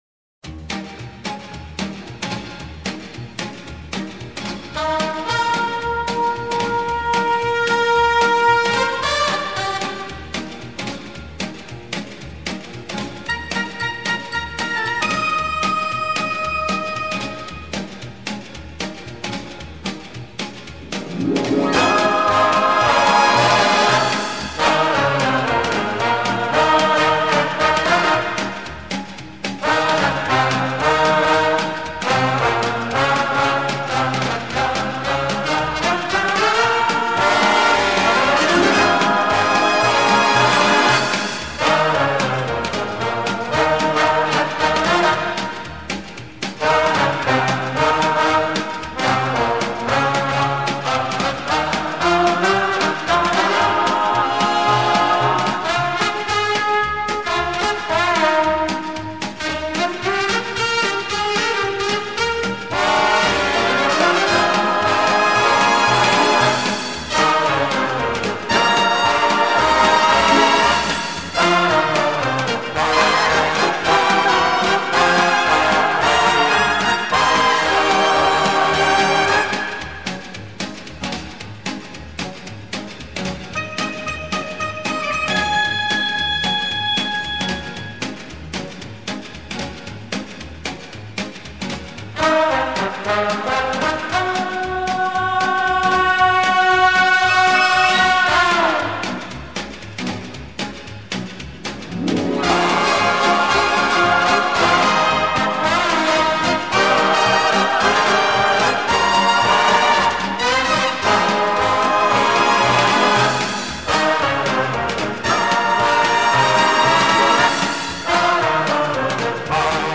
Genre:Easy Listeing